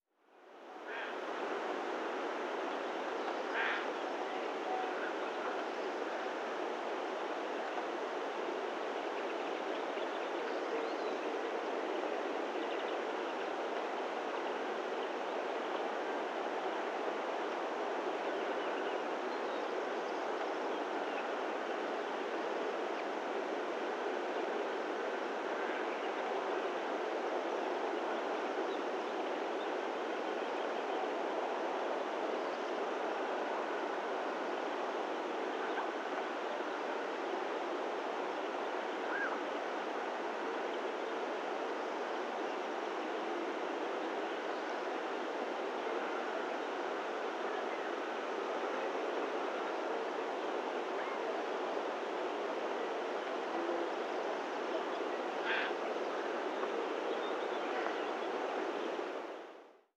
Ambiente de bosque navarro
pato
bosque
Sonidos: Animales
Sonidos: Rural